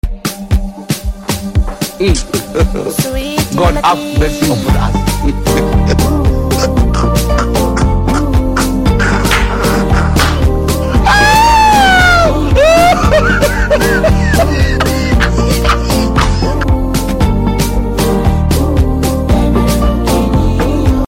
Laugh Out Loud 😂 Sound Effects Free Download